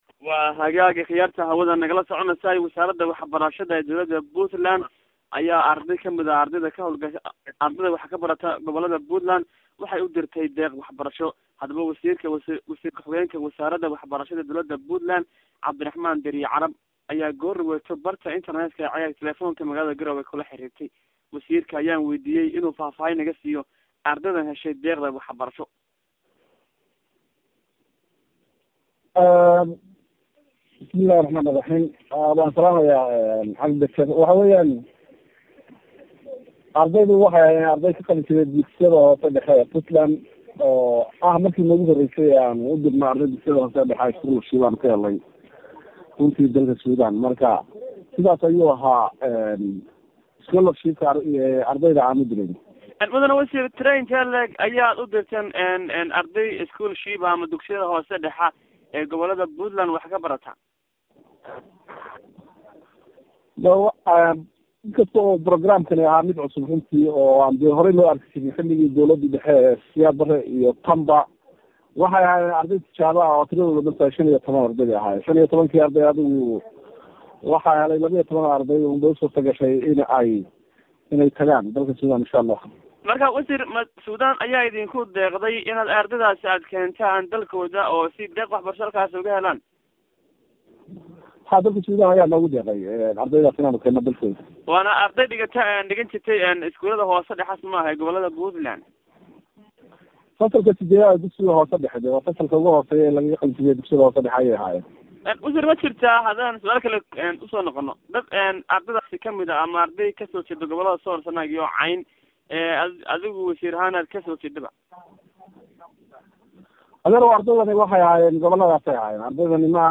Wasaarada Waxbarashada maamulka Puntland ayaa deeq waxbarasho oo wadanka dibadiisa ah markii ugu horaysay u dirtay Raxan ardayda ah oo ka soo jeeda gobolada gobolada SSC hadaba si aanu wax ugu ogaano ardaydaasi heshay deeqda waxbarashada wadanka dibasiisa ayaa Ceegaa Online taleefoonka magaalada Garoowe kula xidhiidhay Wasirku xigeenka Waxbarashada Puntland C/raxmaan Diiriyey Carab.